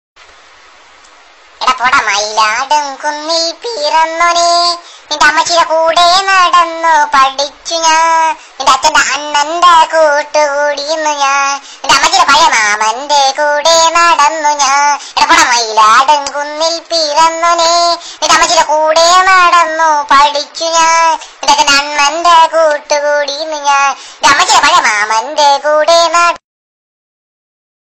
Catégorie Drôle